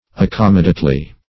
accommodately - definition of accommodately - synonyms, pronunciation, spelling from Free Dictionary Search Result for " accommodately" : The Collaborative International Dictionary of English v.0.48: Accommodately \Ac*com"mo*date*ly\, adv. Suitably; fitly.